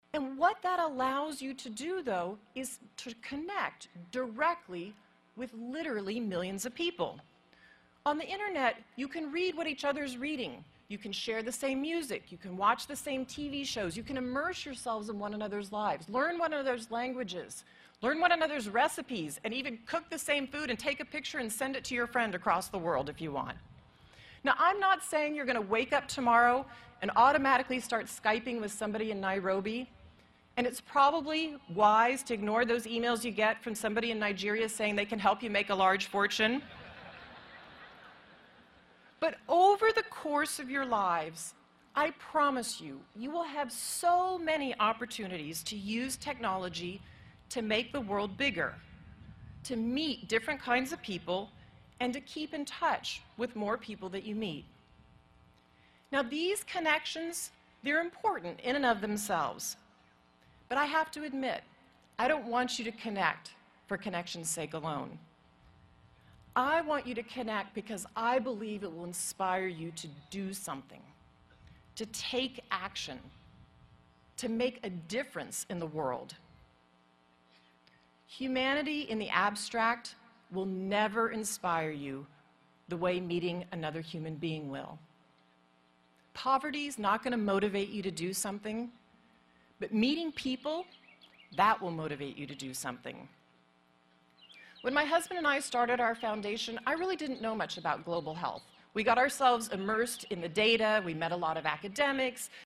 公众人物毕业演讲第349期:梅琳达2013在杜克大学(8) 听力文件下载—在线英语听力室